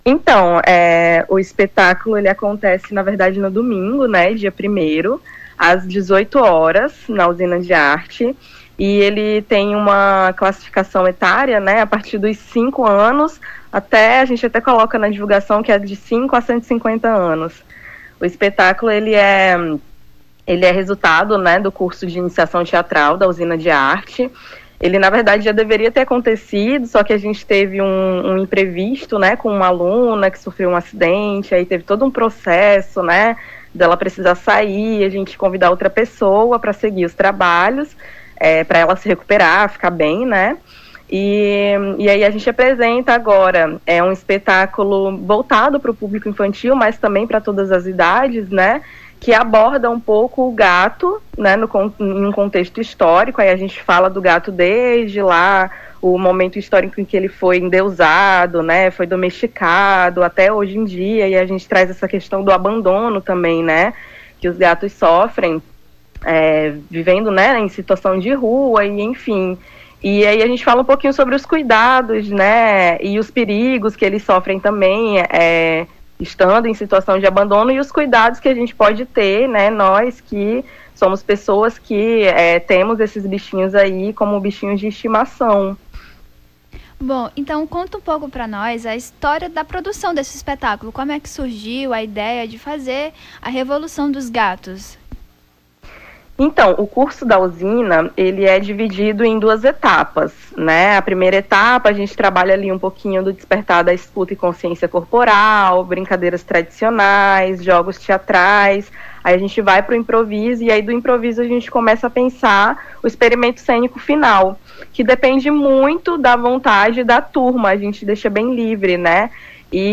Nome do Artista - CENSURA - ENTREVISTA (REVOLUÇÃO DOS GATOS) 29-09-23.mp3